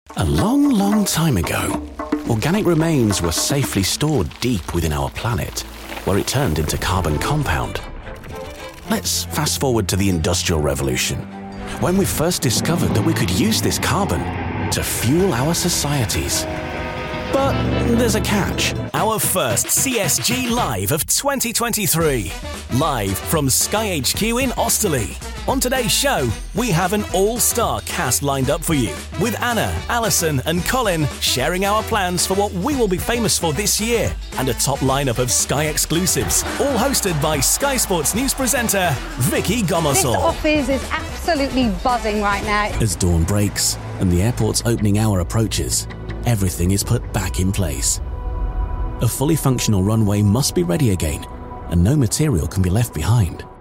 Commercial, Cool, Friendly, Warm, Corporate
Corporate
* Fresh, warm, soft friendly & conversational
* Neutral, RP accent
* Neutral northern english or genuine Yorkshire accent (West Yorkshire/Leeds)